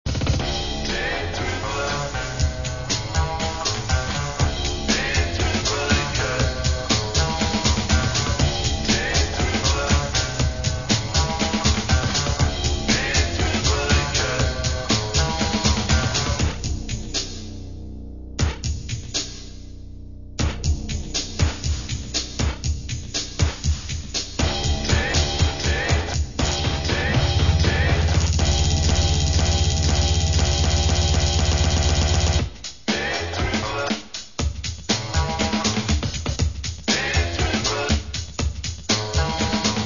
Tercera maqueta con estilo bailable y ritmos rápidos.